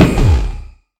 Minecraft Version Minecraft Version latest Latest Release | Latest Snapshot latest / assets / minecraft / sounds / mob / enderdragon / hit2.ogg Compare With Compare With Latest Release | Latest Snapshot